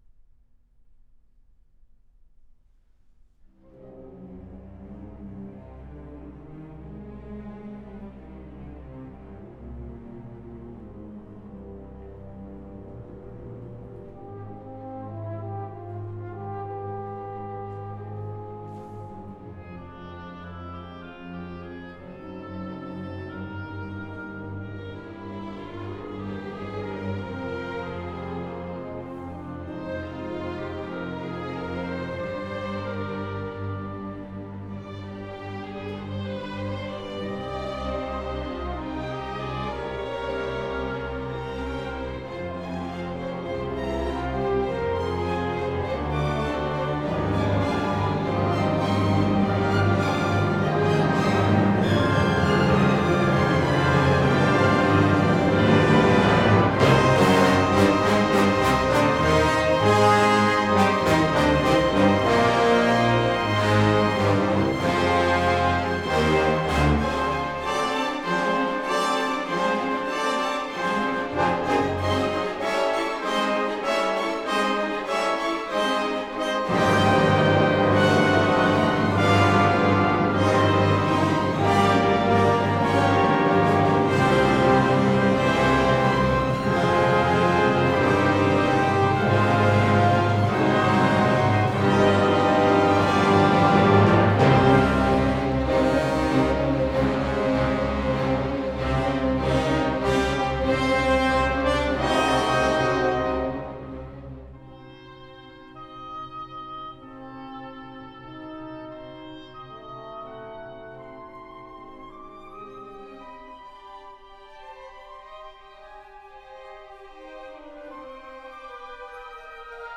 Moores School of Music Orchestra There are many questions about the authenticity of this Symphonic Prelude.